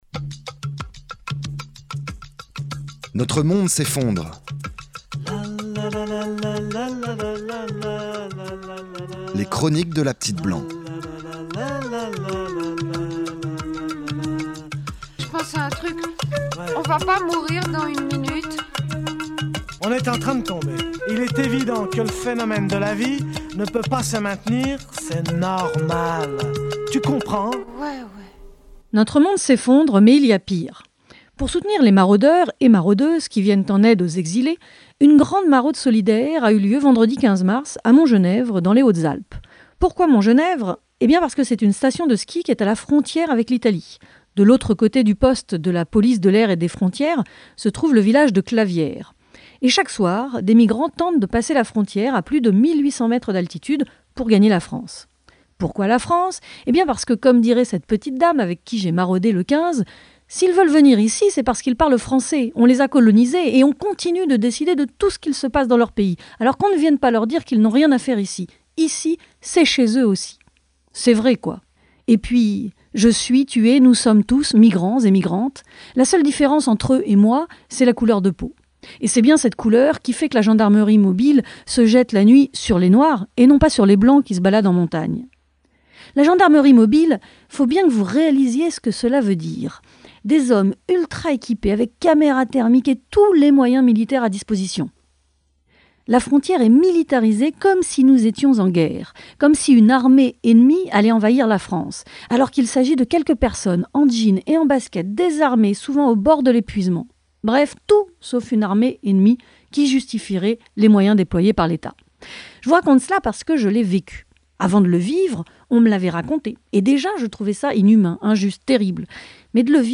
Emission radiophonique en direct tous les mercredis de 19h à 20H30